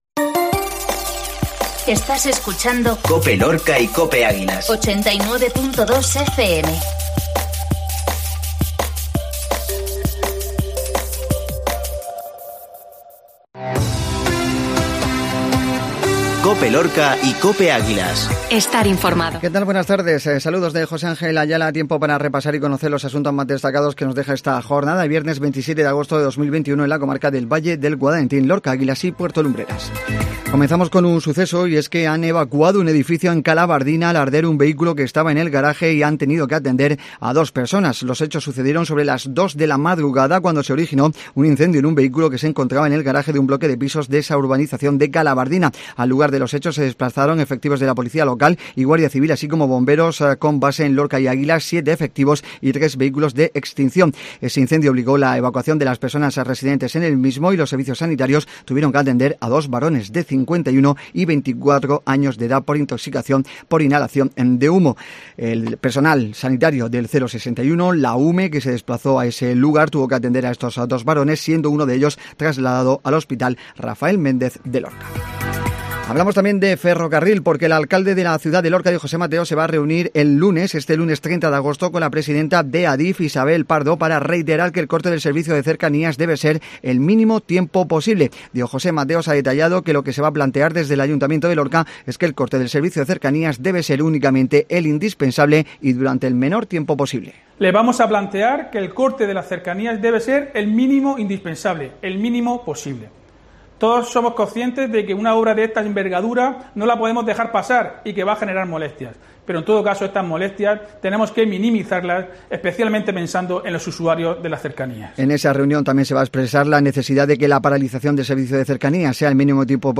INFORMATIVO MEDIODIA VIERNES